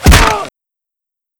body_medium_impact_hard1.wav